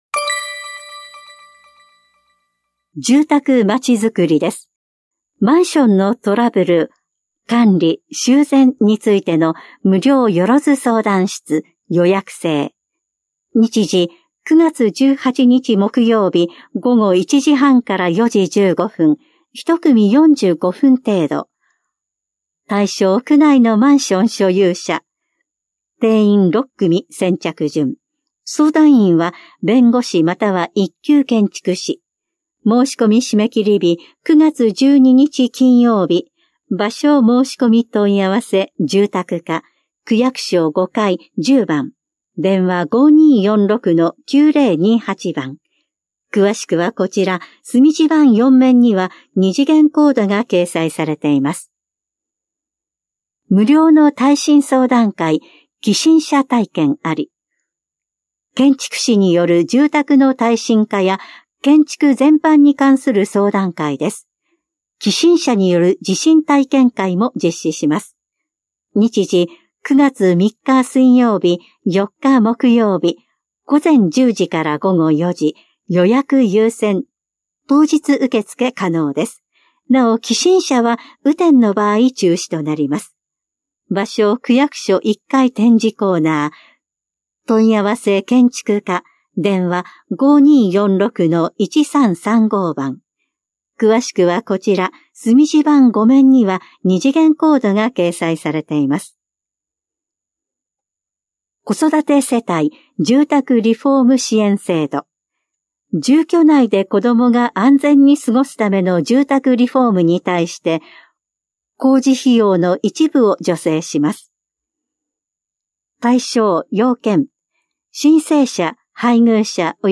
広報「たいとう」令和7年8月20日号の音声読み上げデータです。